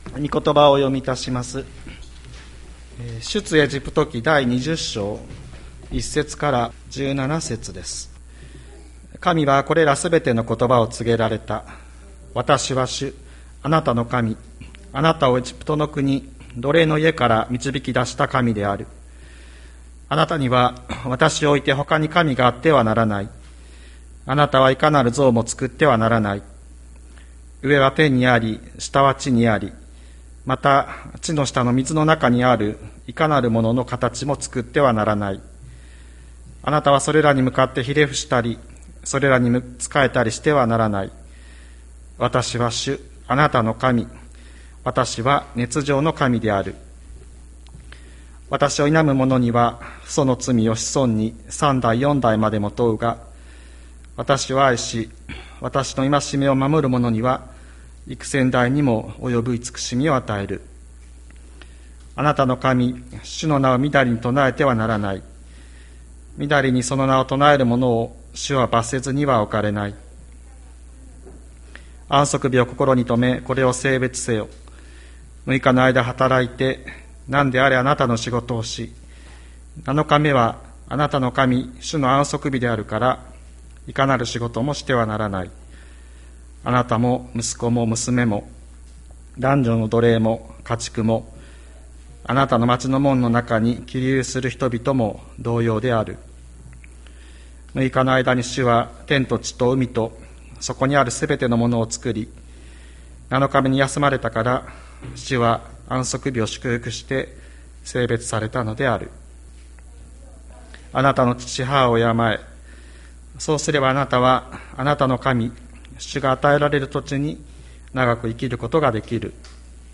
千里山教会 2022年06月19日の礼拝メッセージ。